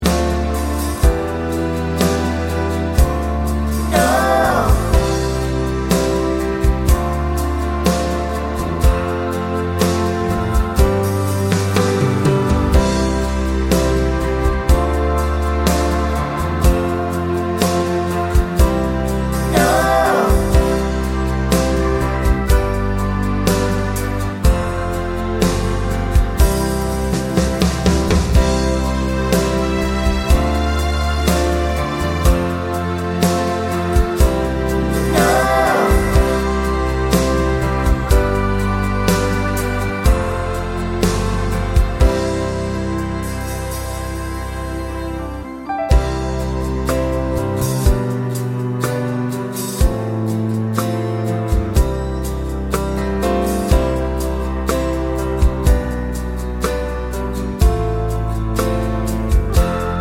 no Backing Vocals Irish 3:31 Buy £1.50